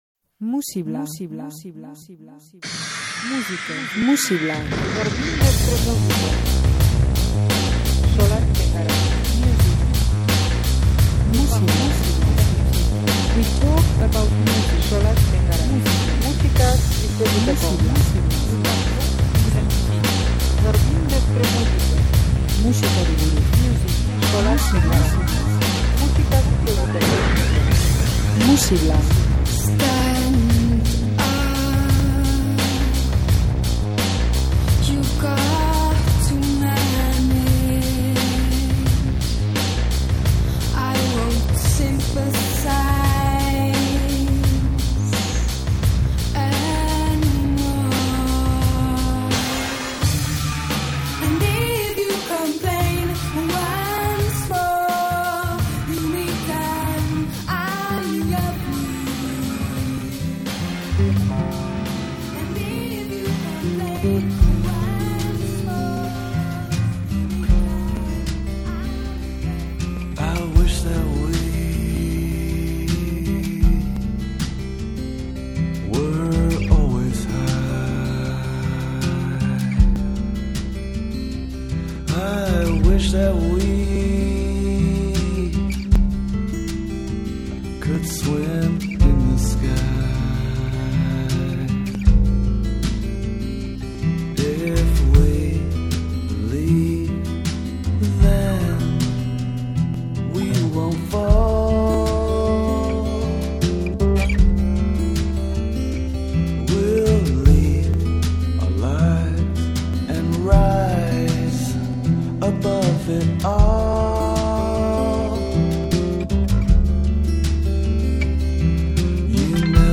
egungo soul musika entzungo dugu